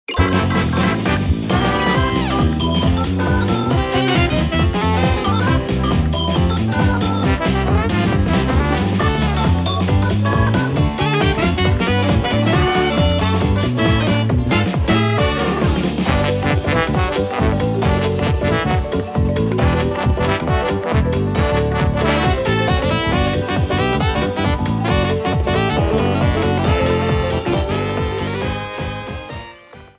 Shortened, applied fade-out and converted to oga